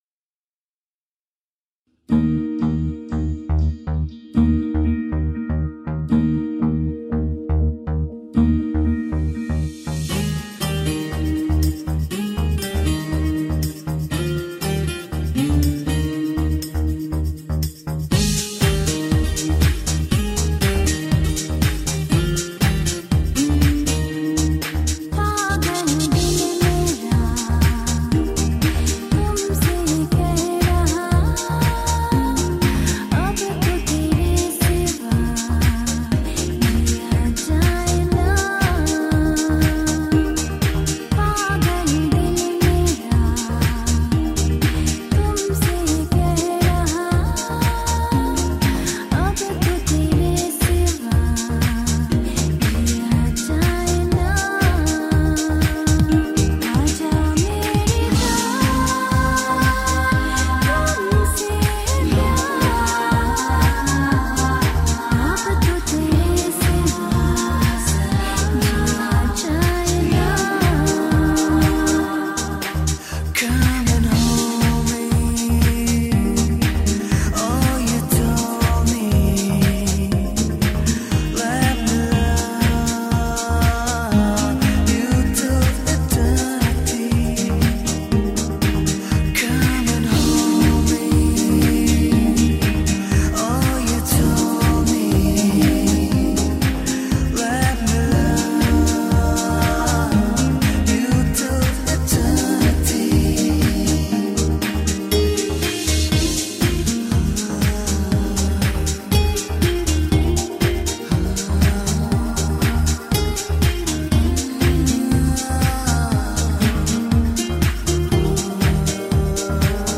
Tagged as: Alt Rock, Ambient